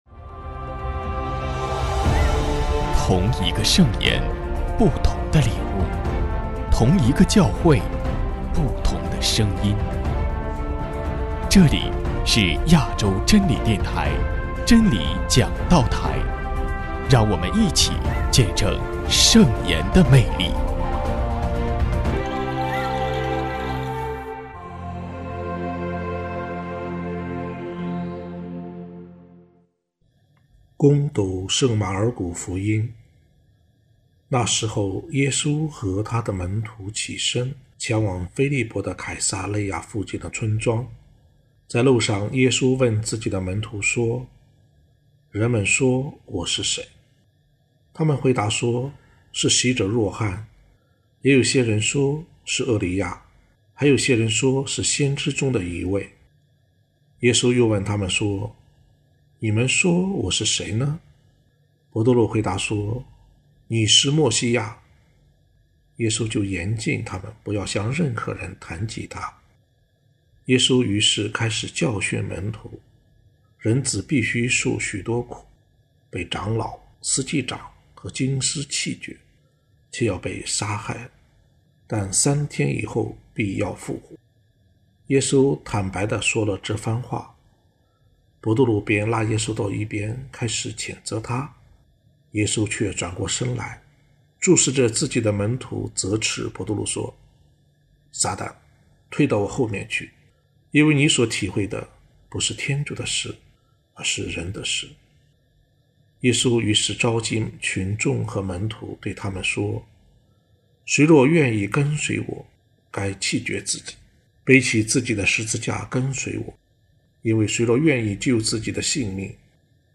——基督的福音 证道 主题： 寻找耶稣，却舍不下自己的小算盘 有一位教授，乘坐一条小渔船过江。